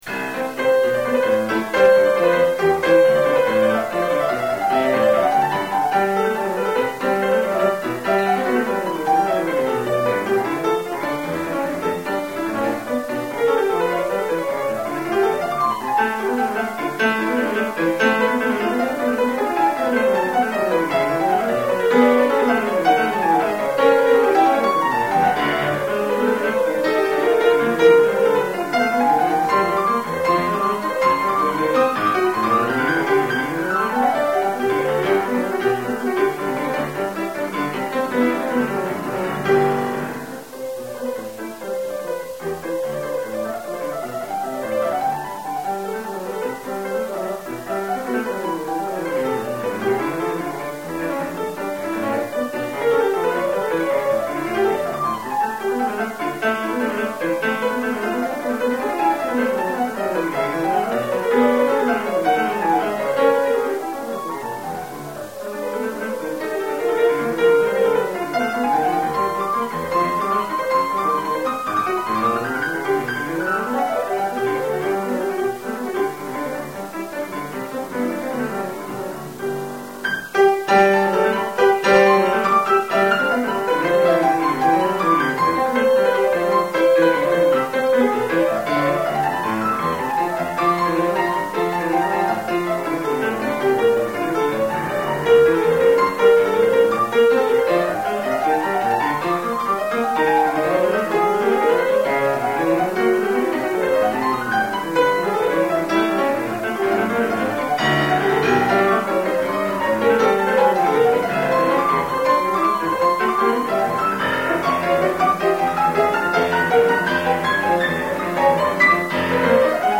2.5-Part Invention'' (live recording with me at the piano), and a short program note.